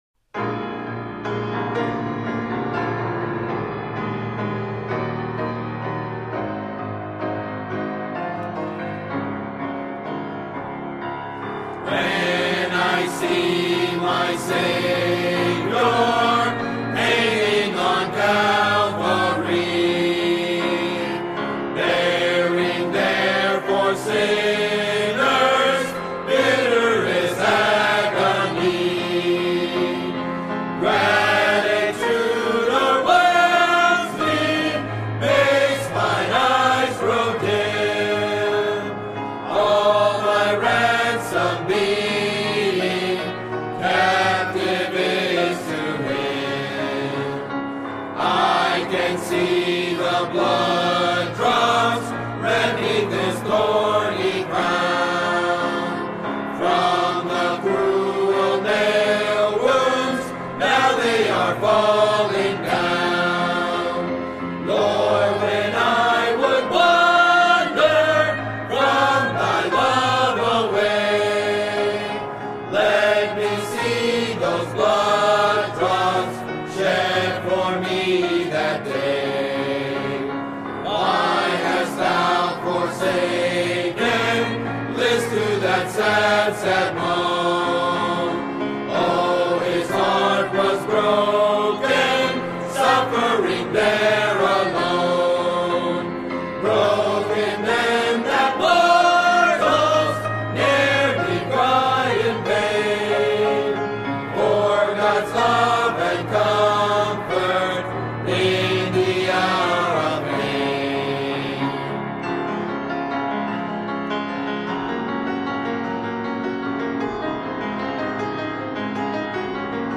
Music Hymns